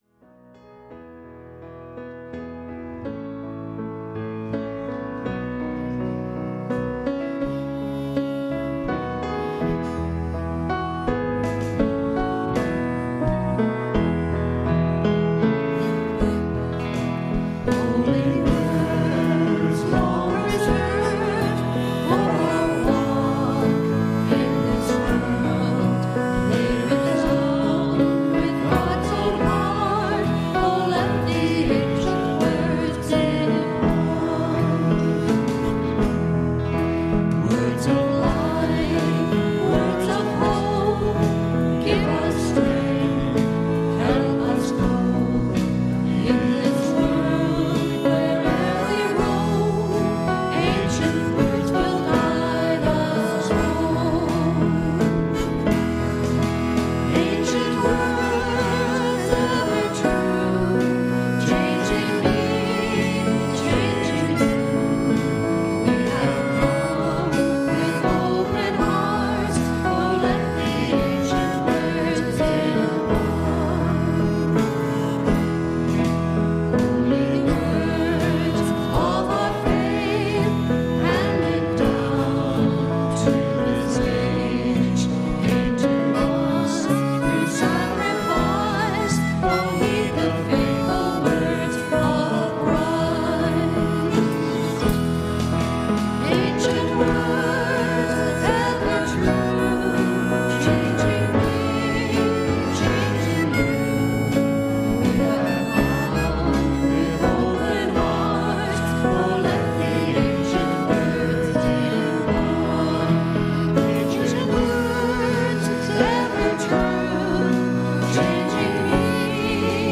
Podcast from Christ Church Cathedral Fredericton
WORSHIP - 10:30 a.m. The Epiphany (transferred)